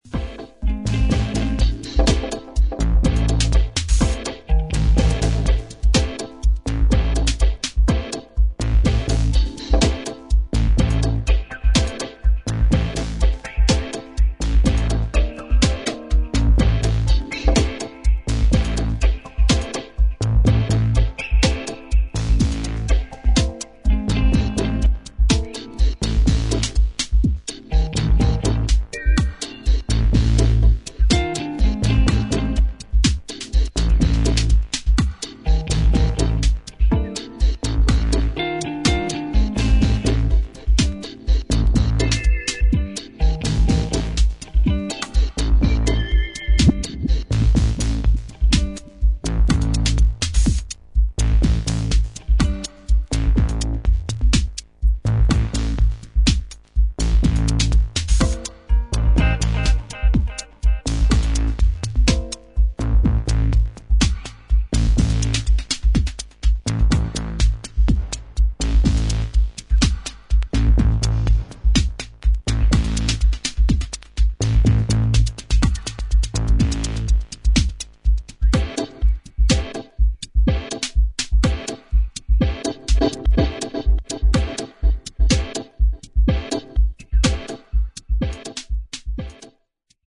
ひねりの効いたリズム・プログラミングが聴きどころの